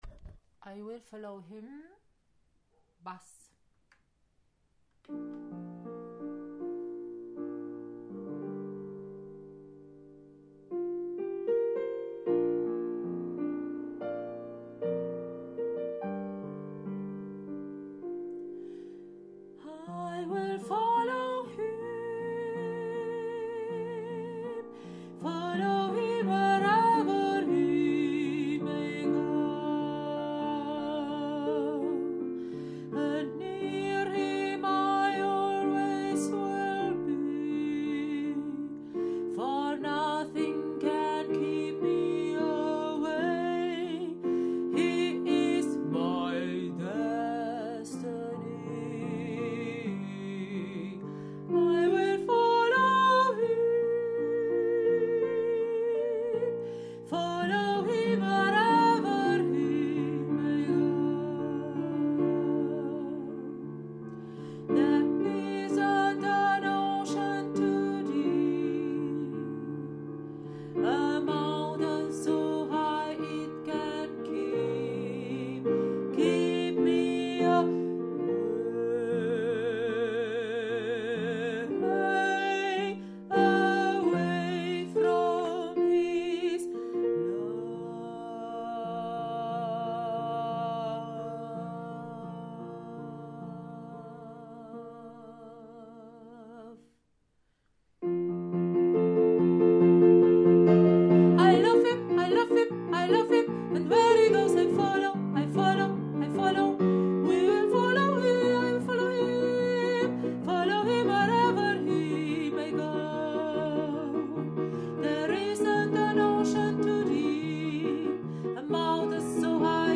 I will follow him – Bass
I-will-follow-him-Bass.mp3